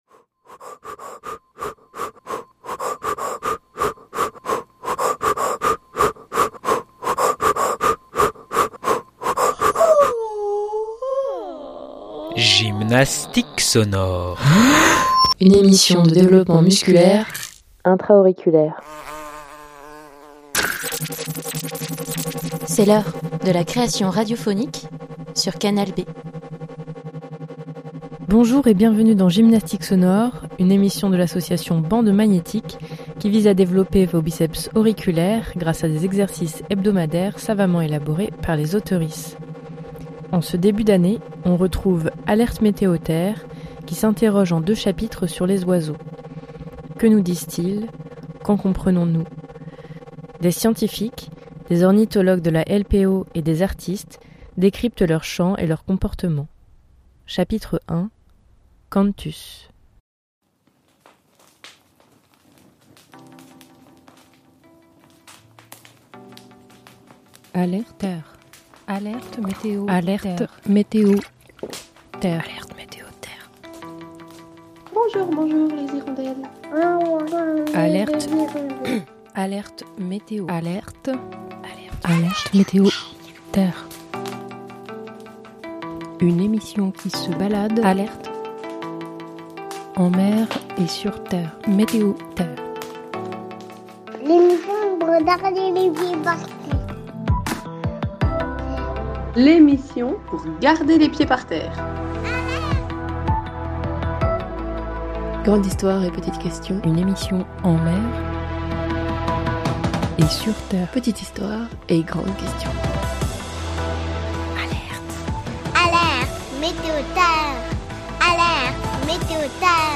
Le premier chapitre donne le chant et la parole aux oiseaux. Que nous disent-ils, qu'en comprenons-nous ? Des scientifiques, des ornithologues de la LPO et des artistes décryptent leurs chants et leurs comportements.